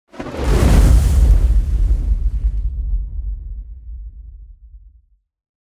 دانلود آهنگ آتش 19 از افکت صوتی طبیعت و محیط
دانلود صدای آتش 19 از ساعد نیوز با لینک مستقیم و کیفیت بالا
جلوه های صوتی